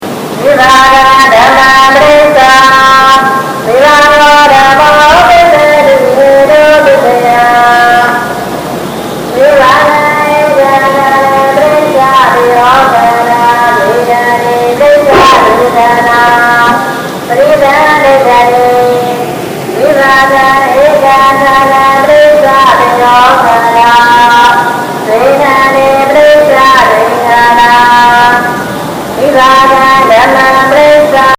Während des ganzen späten Nachmittags und des Nachtessens rezitierte ein Mönch einer nahe unserm Hotel gelegenen Tempels Verse aus der Lehre Buddhas. Wir hatten den Eindruck, es sei immer dieselbe Zeile.